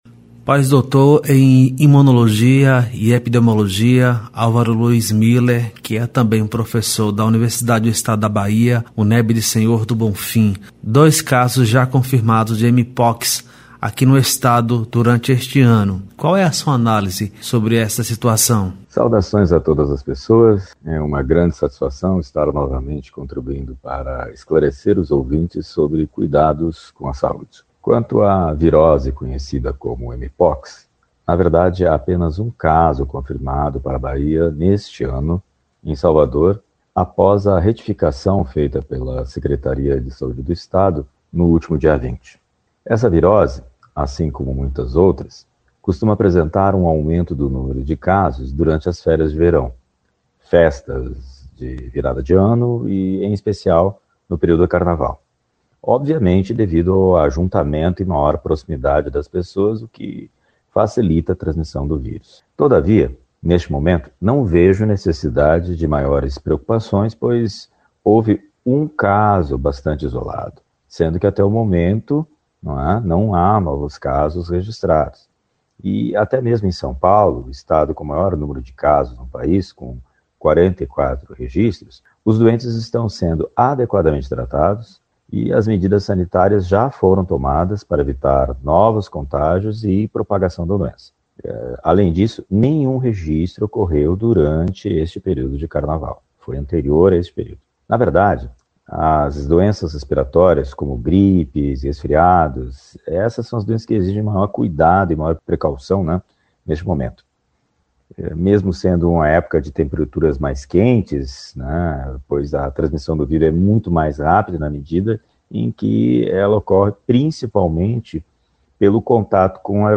Imunologista e epidemiologista